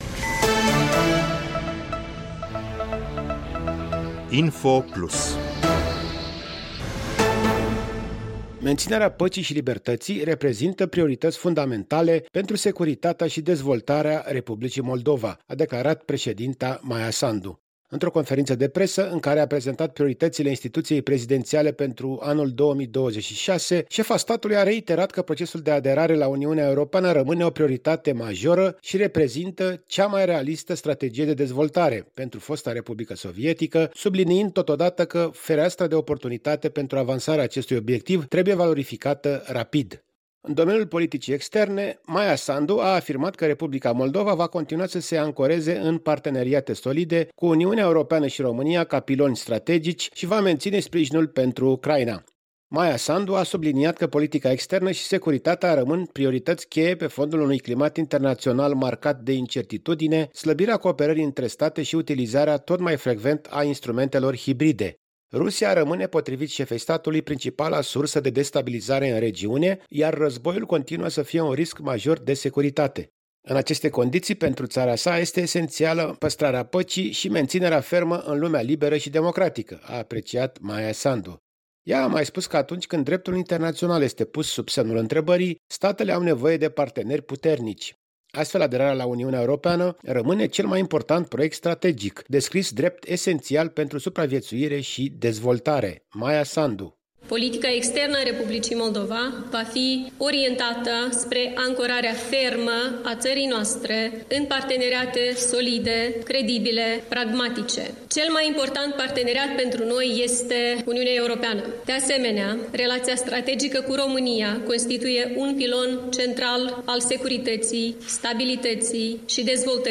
Într-o conferinţă de presă, în care a prezentat priorităţile instituţiei prezidenţiale pentru 2026, şefa statului a reiterat că procesul de aderare la Uniunea Europeană rămâne o prioritate majoră şi reprezintă „cea mai realistă strategie de dezvoltare” pentru fosta republică sovietică, subliniind că fereastra de oportunitate pentru avansarea acestui obiectiv trebuie valorificată rapid.